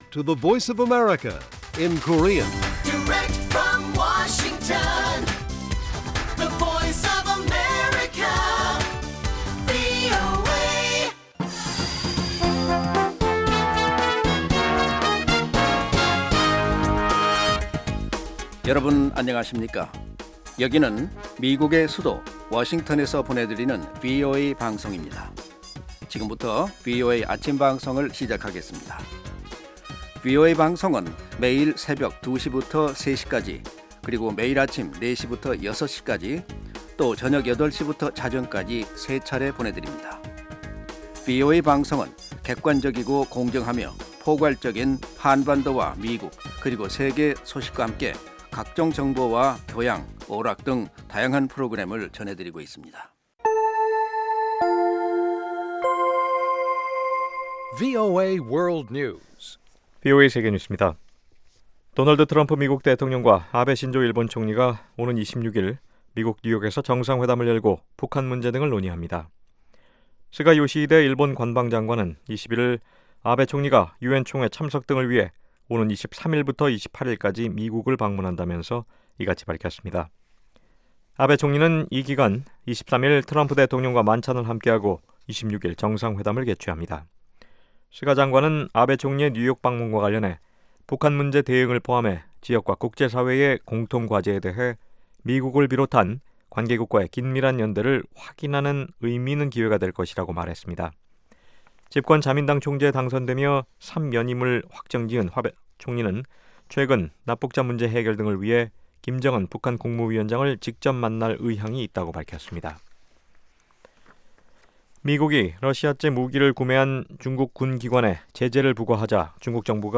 세계 뉴스와 함께 미국의 모든 것을 소개하는 '생방송 여기는 워싱턴입니다', 2018년 9월 22일아침 방송입니다. ‘지구촌 오늘’에서는 미국이 중국 중앙군사위 산하기관에 제재를 단행했다는 소식, ‘아메리카 나우’에서는 도널드 트럼프 행정부가 새로운 사이버 전략을 발표했다는 이야기 전해드립니다.